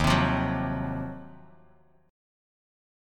Listen to Ebm6add9 strummed